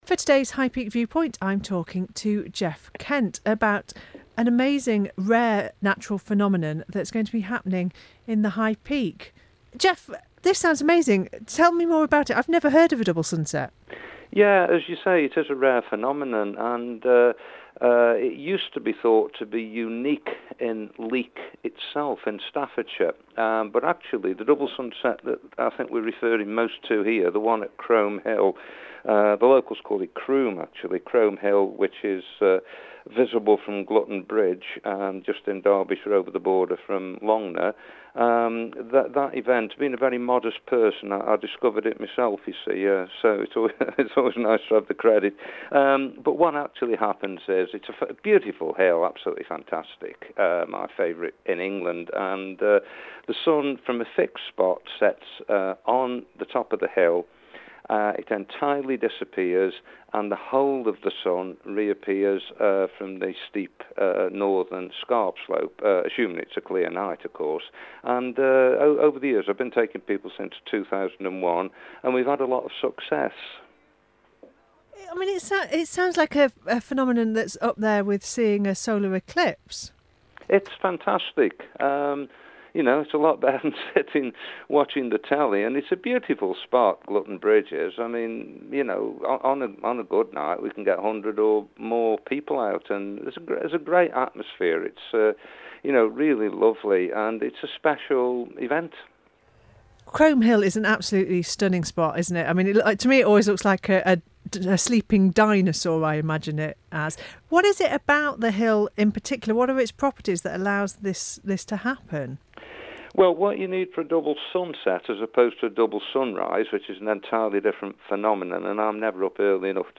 talks to High Peak and Ashbourne Radio about the rare phenomenon of the double sunset on Chrome Hill.